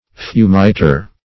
fumiter - definition of fumiter - synonyms, pronunciation, spelling from Free Dictionary Search Result for " fumiter" : The Collaborative International Dictionary of English v.0.48: Fumiter \Fu"mi*ter`\, n. (Bot.)